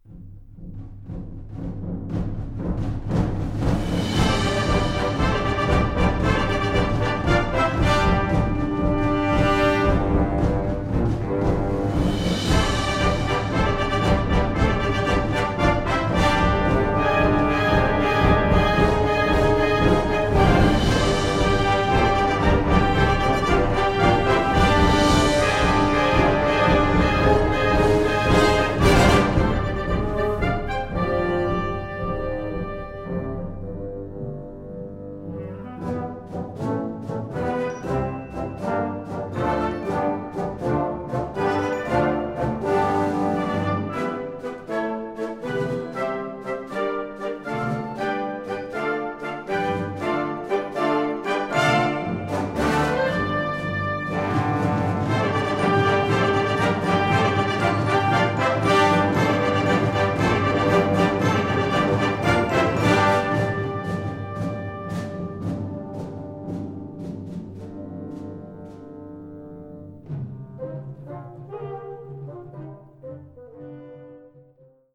Categorie Harmonie/Fanfare/Brass-orkest
Subcategorie Programmatische muziek
Bezetting Ha (harmonieorkest)